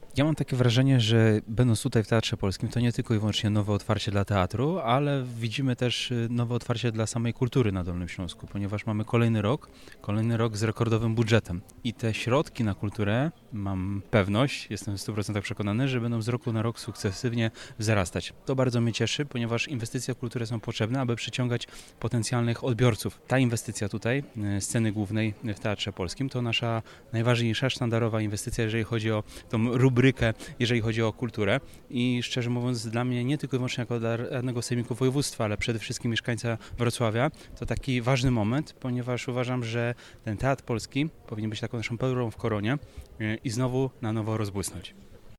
O tym, jak Urząd Marszałkowski wspiera dolnośląską kulturę mówi Mateusz Jędrachowicz, radny Sejmiku Województwa Dolnośląskiego.